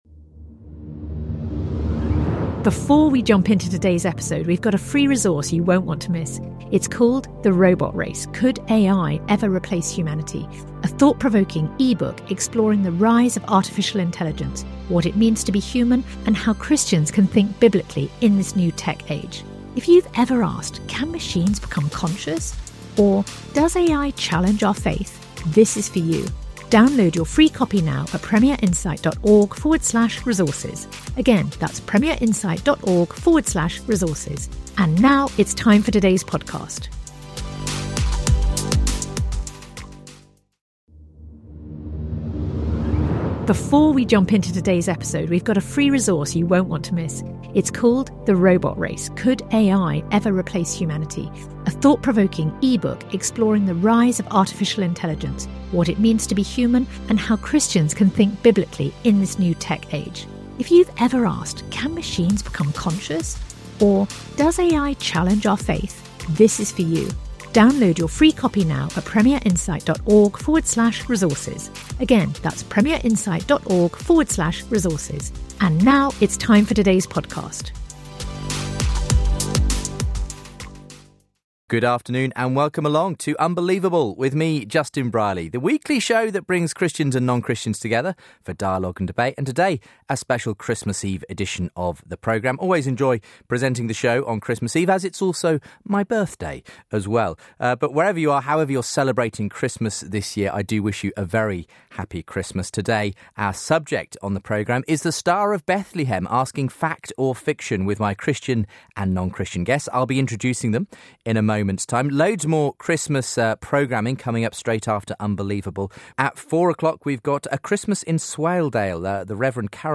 Christian guest
atheist